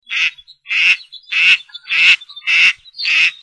Femelle Chipeau
Anas strepera